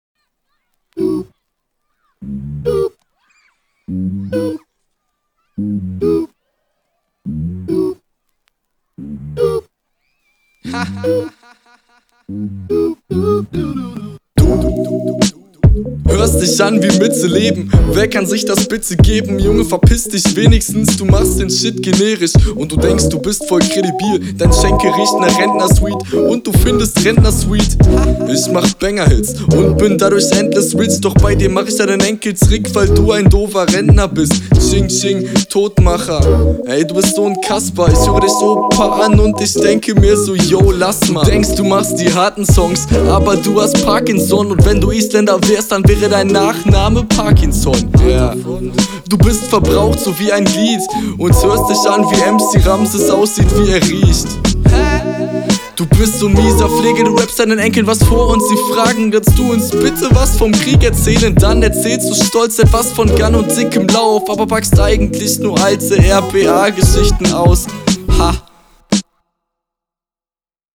Yüah Pop-Beats.
rap wieder sehr cool. find super nice wie du betonst. beat auch voll schön. die …